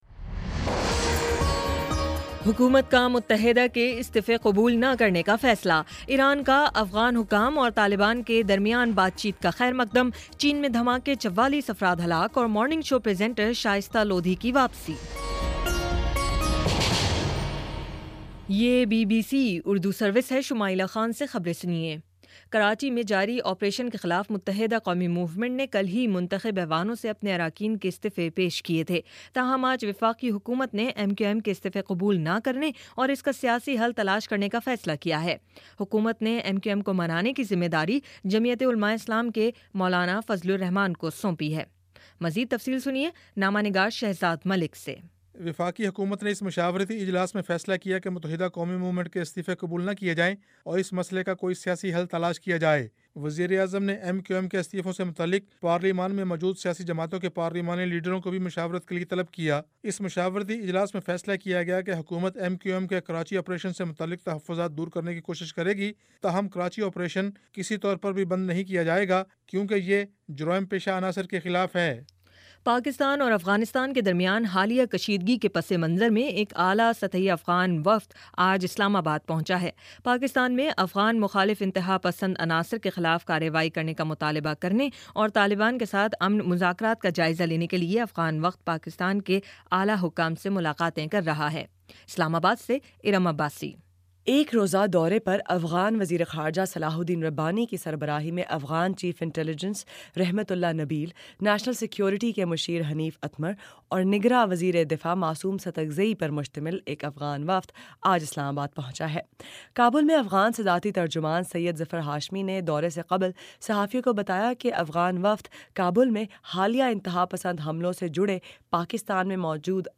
اگست 12: شام پانچ بجے کا نیوز بُلیٹن